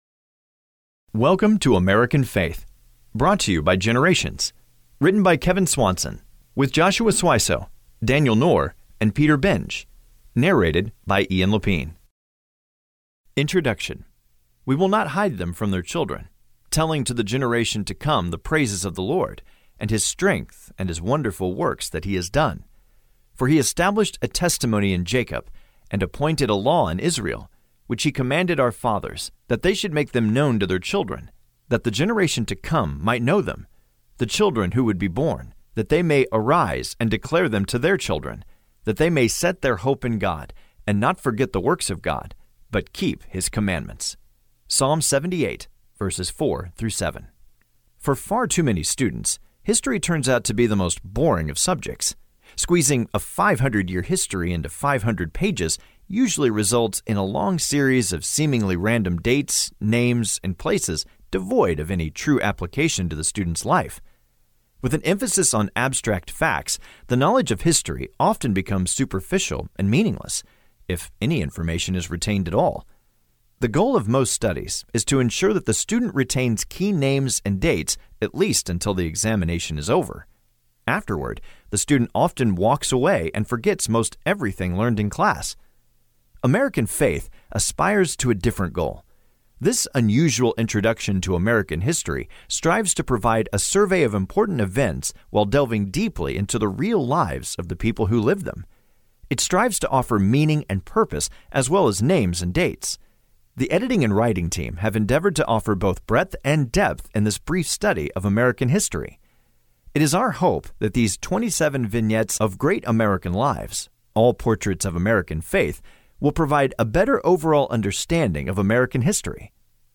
American Faith - Audiobook